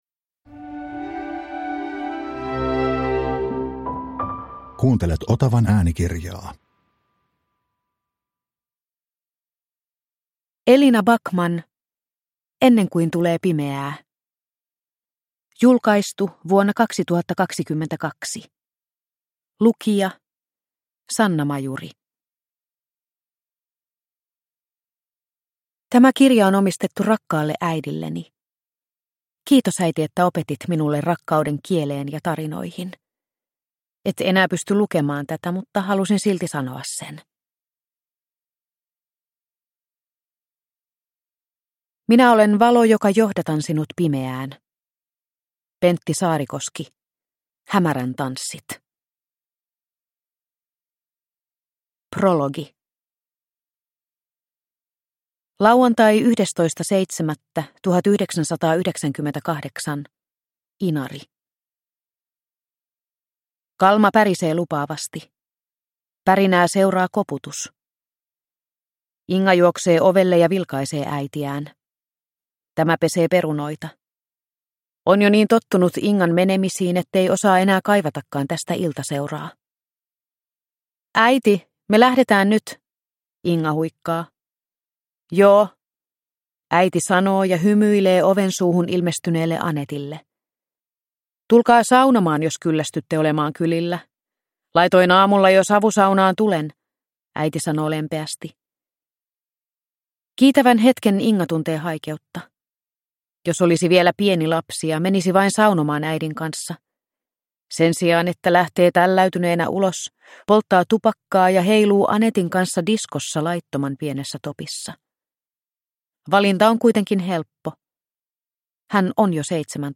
Ennen kuin tulee pimeää – Ljudbok – Laddas ner